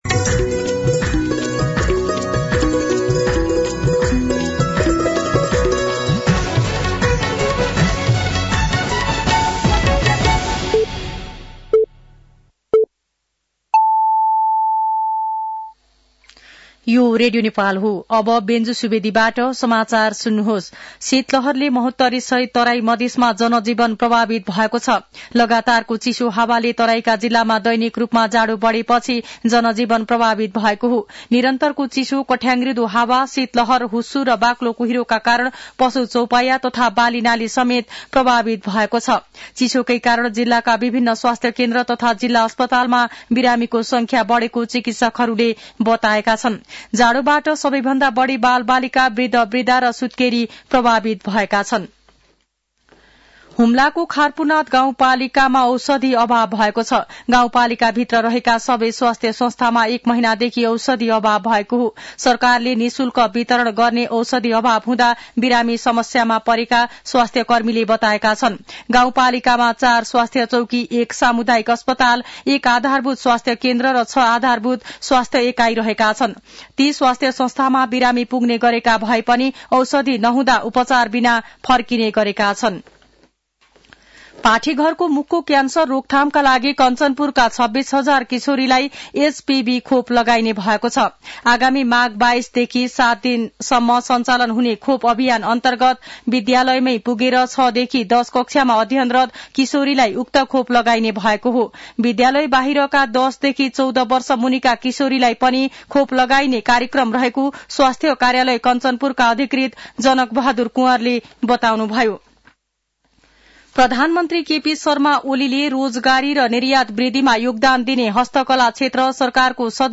दिउँसो ४ बजेको नेपाली समाचार : २७ पुष , २०८१
4-pm-nepali-news-4.mp3